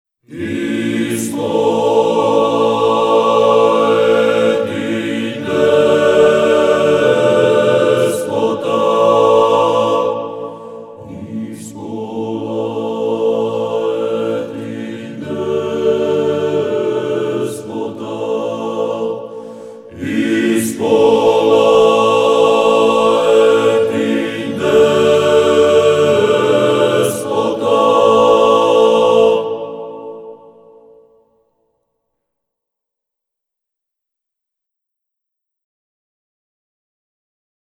Listen to a recording by the Prešov Seminary Choir)